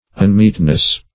-- Un*meet"ness , n. [1913 Webster]